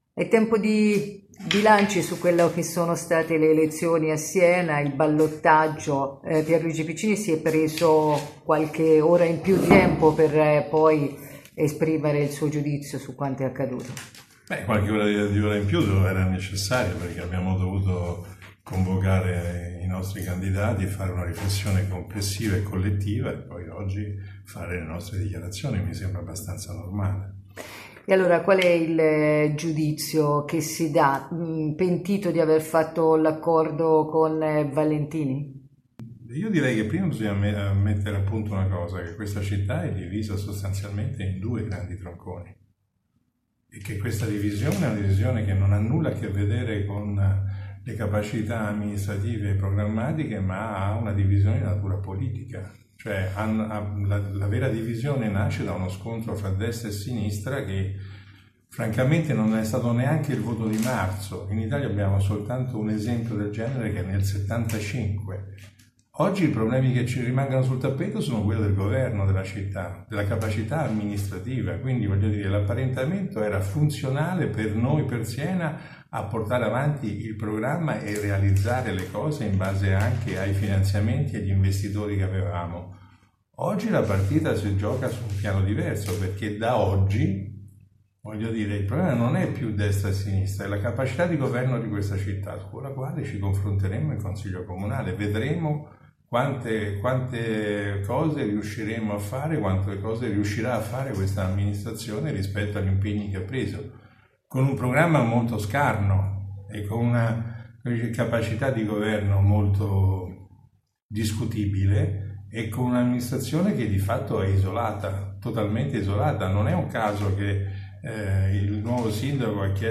A distanza di due giorni dal ballottaggio il “socio” di Valentini nell’apparentamento per la poltrona di sindaco, ha rotto il silenzio e fatto l’esame del voto in una conferenza stampa.